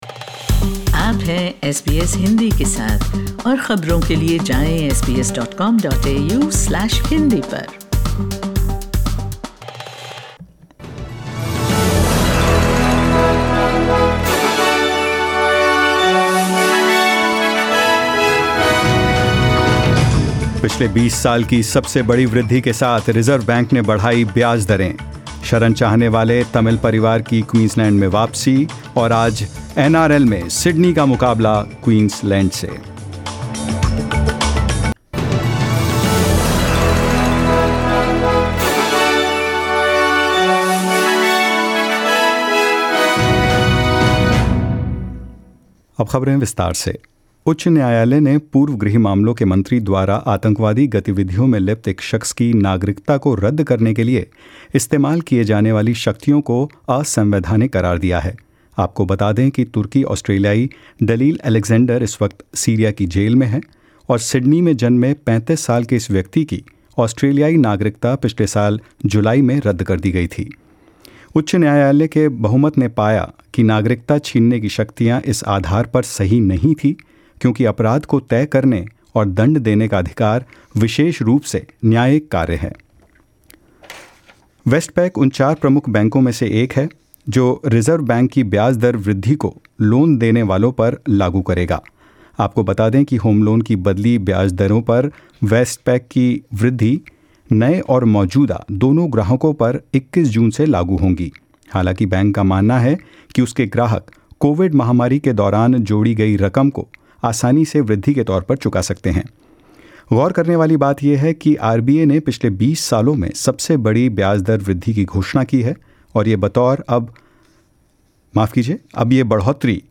In this latest SBS Hindi bulletin: The High Court rules terrorism laws invalid; A Tamil asylum-seeker family on their way home to rural Queensland and more